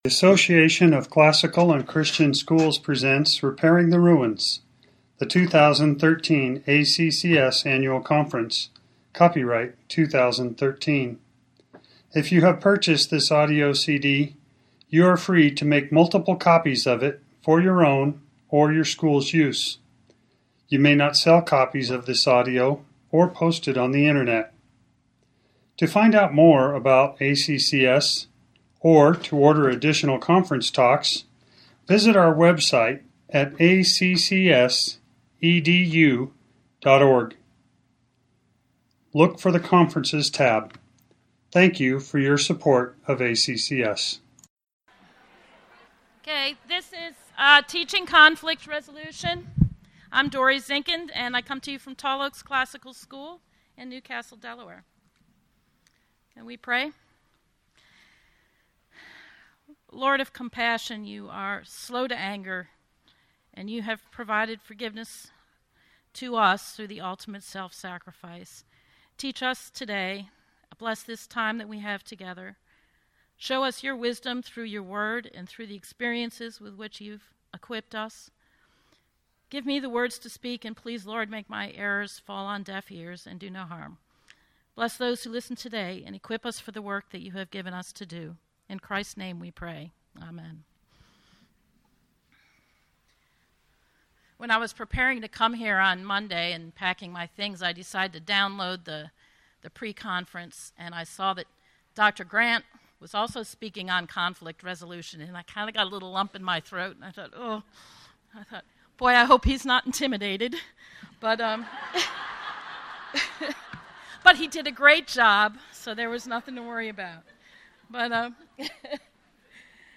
2013 Workshop Talk | 0:59:15 | Teacher & Classroom
The Association of Classical & Christian Schools presents Repairing the Ruins, the ACCS annual conference, copyright ACCS.